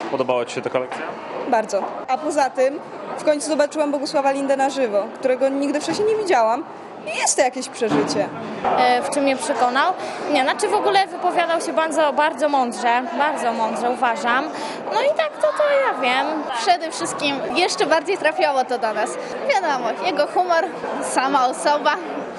Bogusław Linda mówi o Unii Europejskiej (PAP)
W przekonywanie Polaków do Unii Europejskiej zaanagżowali się już nawet aktorzy. Bogusław Linda spotkał się dzisiaj z licealistami w Białobrzegach.